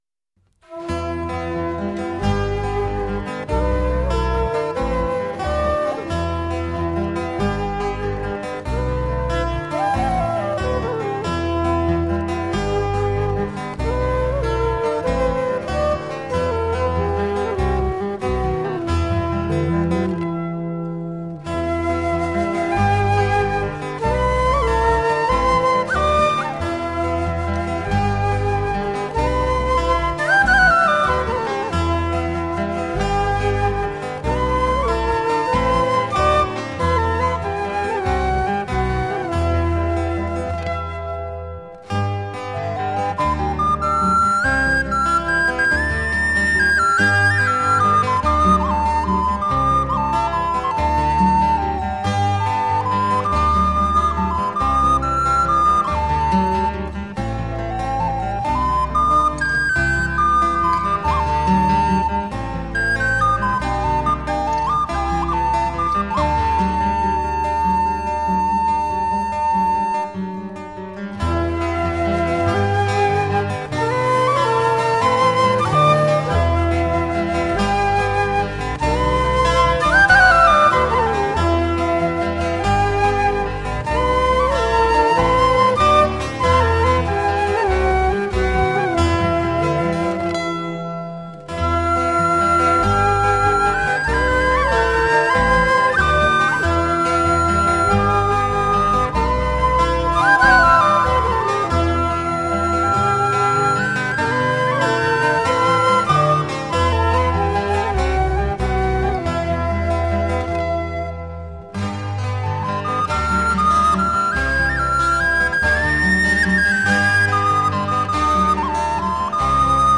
Improvised acoustic guitar with textures of chiming chords.
It is played by real people, not computers.